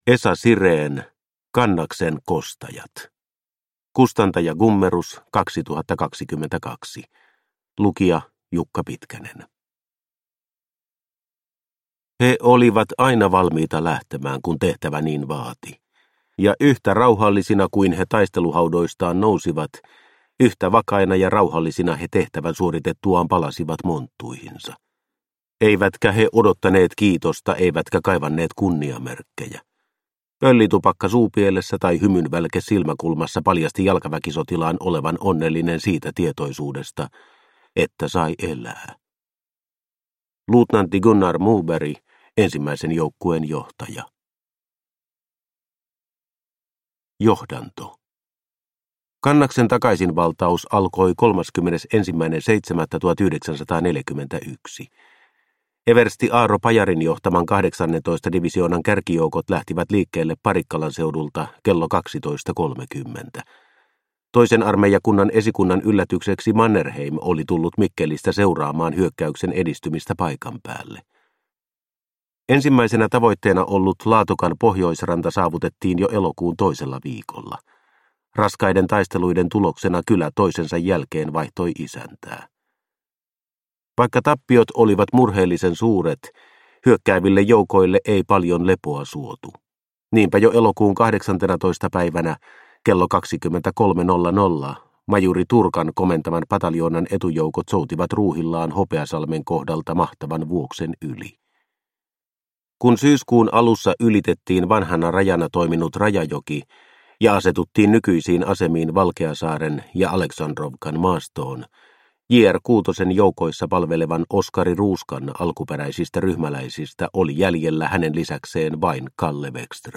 Kannaksen kostajat – Ljudbok – Laddas ner